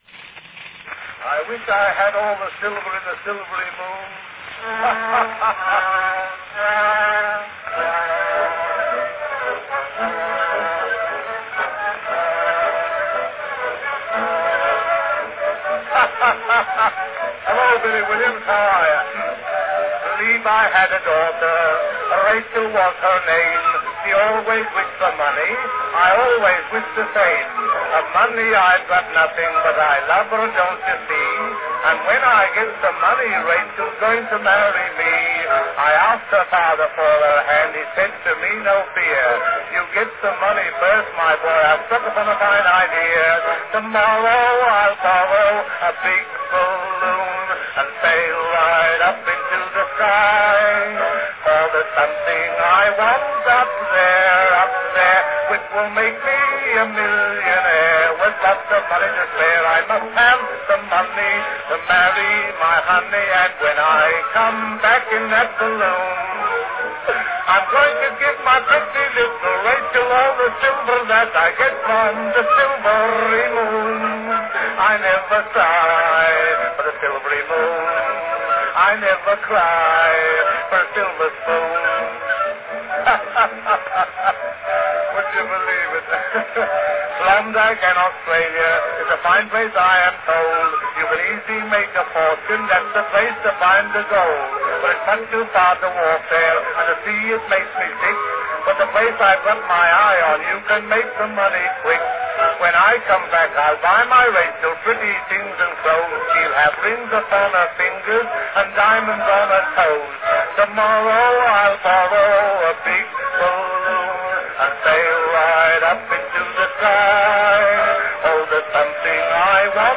Category Comic song